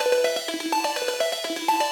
SaS_Arp04_125-C.wav